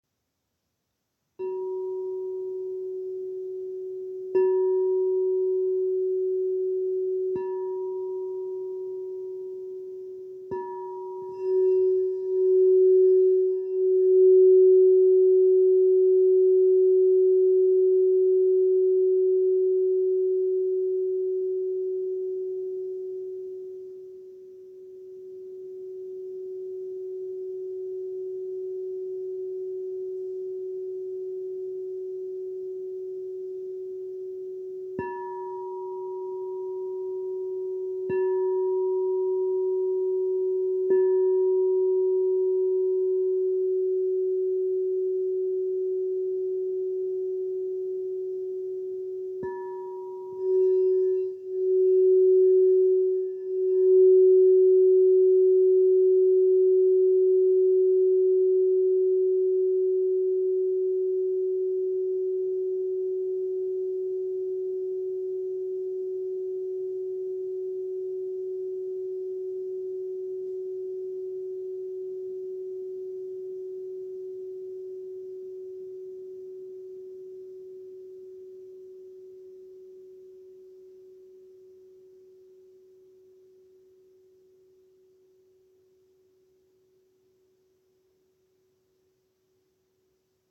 "Zpívající" Křišťálové mísy
Mísa tón G velikost 8" (20,5cm)
Ukázka mísa G
Mísa G.m4a